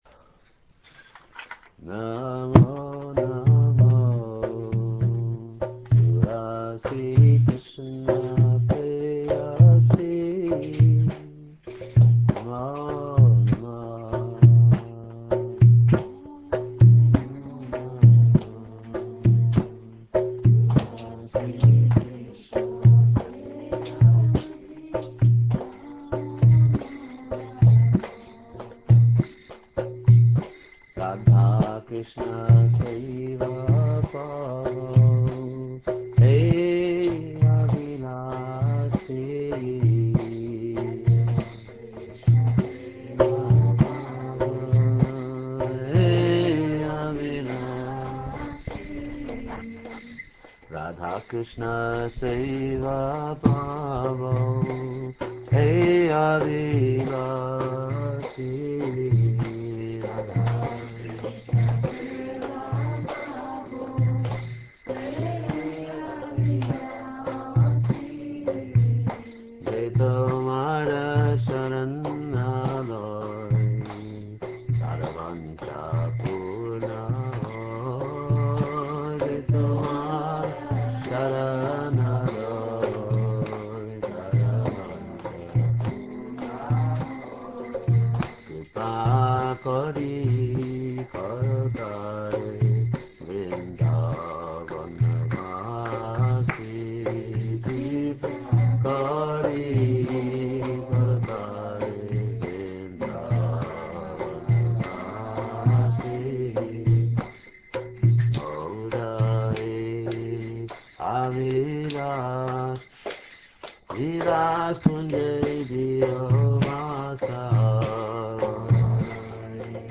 tulasikirtan.rm